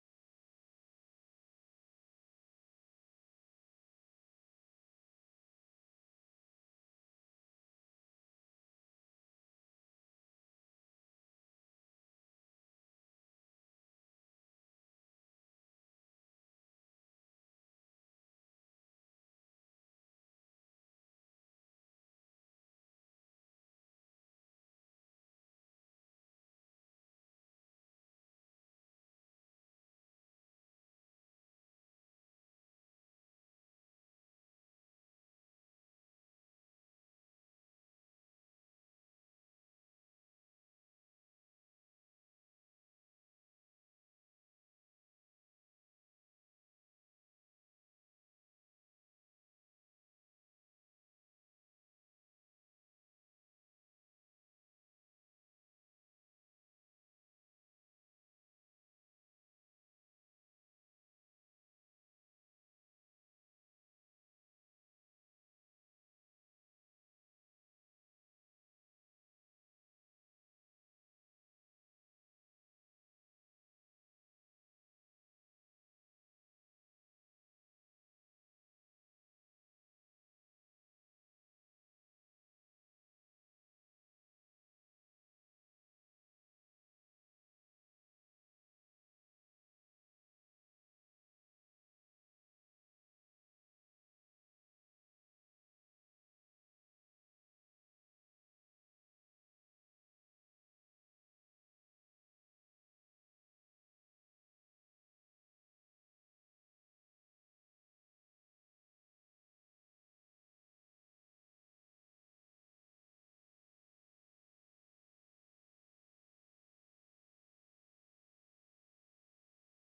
خطبة - الإحسان من خلال سورة يوسف